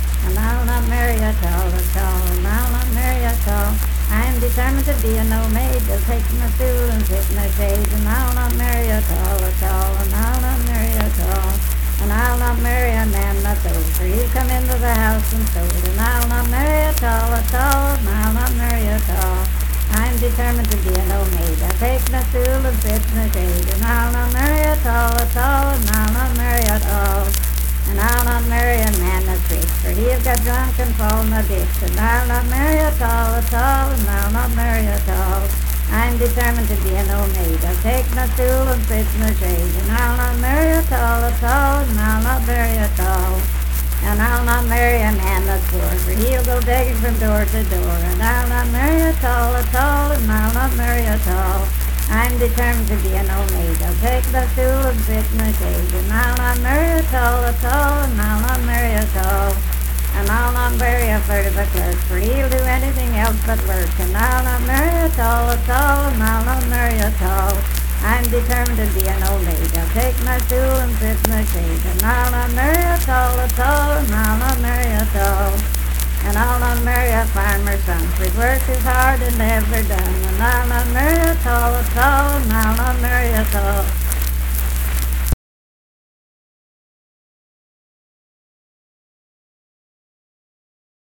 Unaccompanied vocal music performance
Verse-refrain 1 (4) & R (4-6).
Voice (sung)